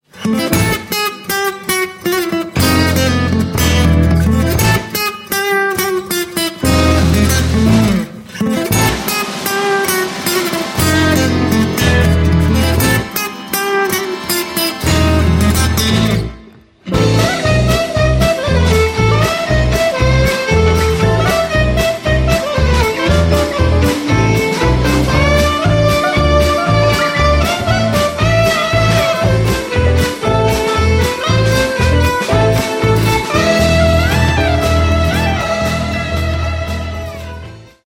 SWING Z KREMLA